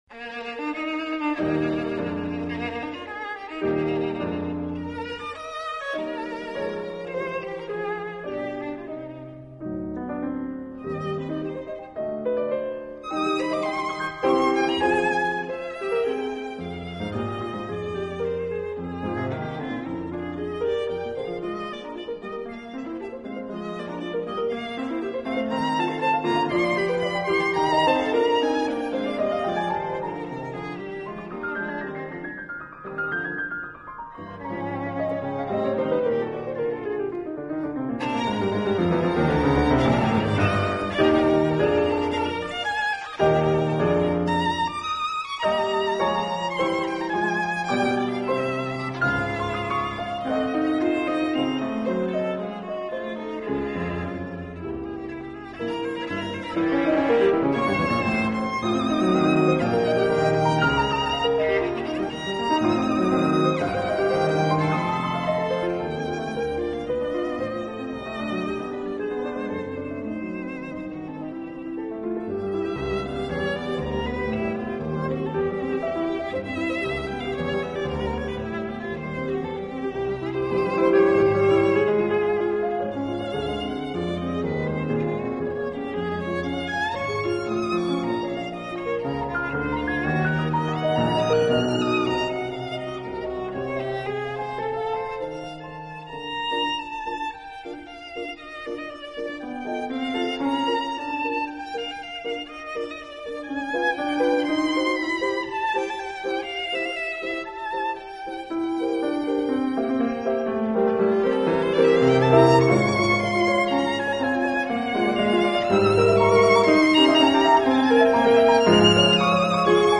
降e调小提琴奏鸣曲 -下载地址列表-乐器学习网
0296-降e调小提琴奏鸣曲.mp3